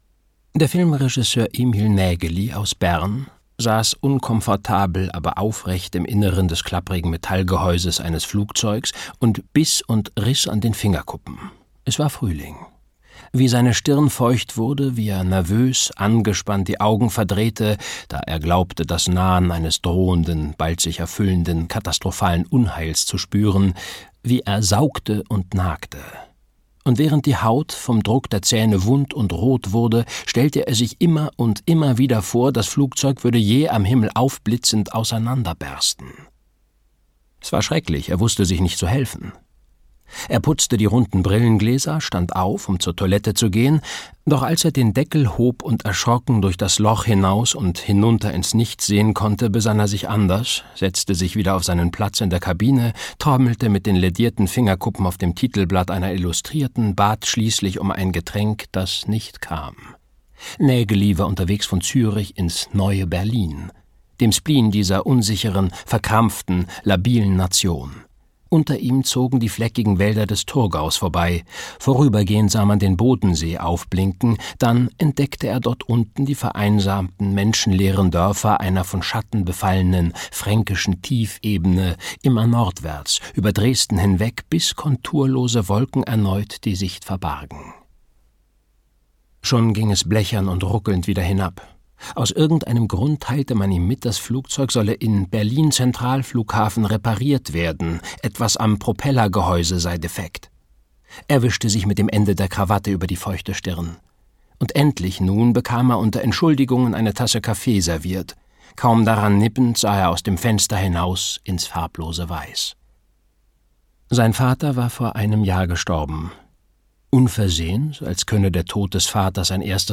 Die Toten - Christian Kracht - Hörbuch